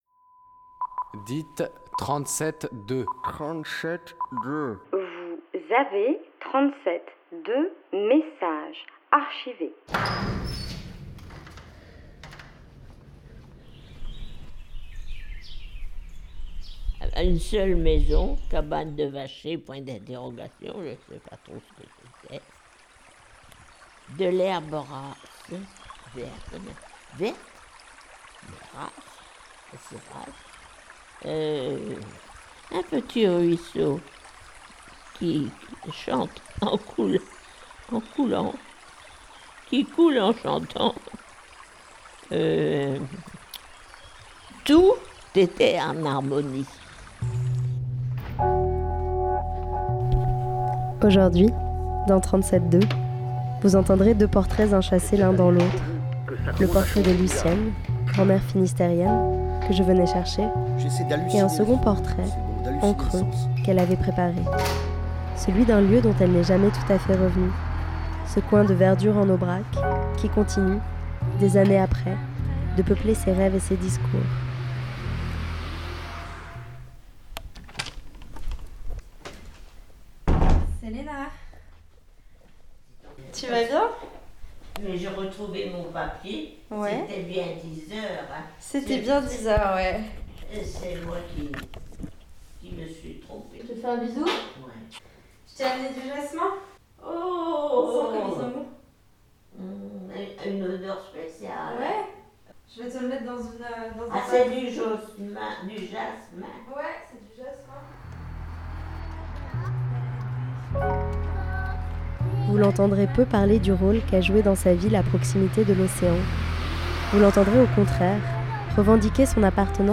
la voix têtue mais tenace